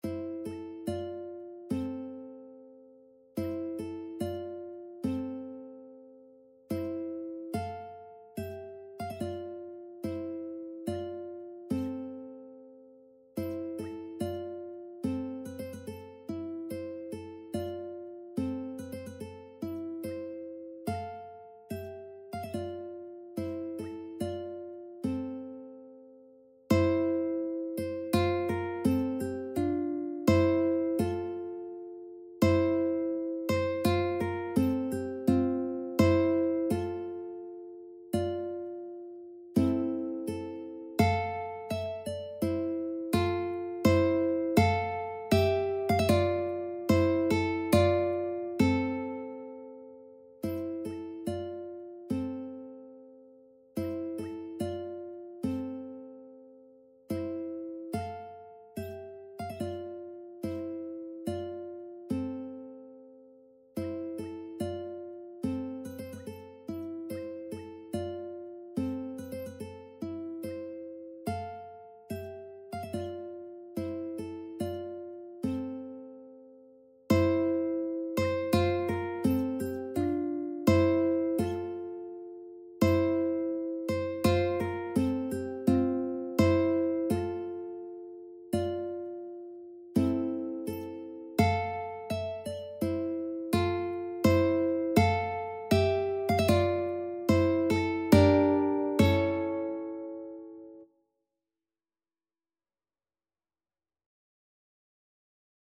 Classical Balakirev, Mily The Song of the Volga Boatmen Ukulele version
C major (Sounding Pitch) (View more C major Music for Ukulele )
Andante = 72
4/4 (View more 4/4 Music)
Ukulele  (View more Intermediate Ukulele Music)
Classical (View more Classical Ukulele Music)
Volga_Boatmen_Song_UKE.mp3